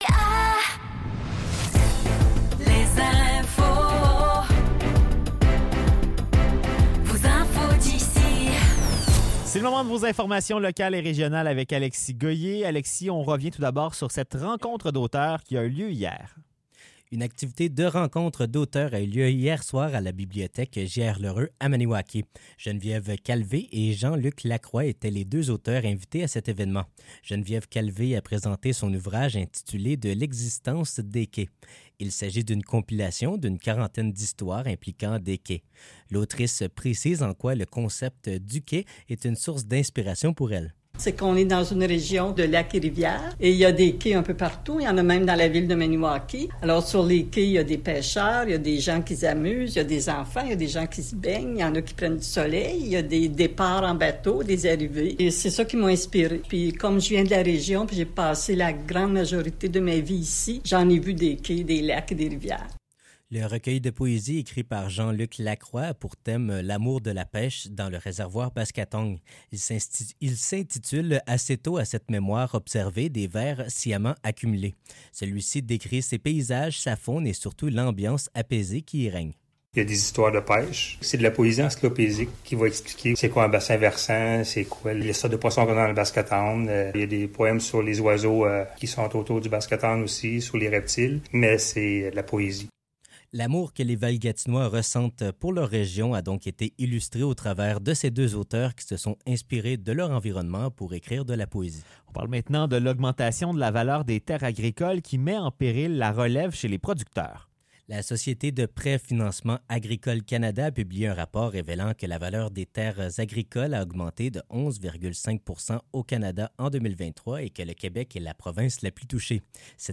Nouvelles locales - 24 mai 2024 - 16 h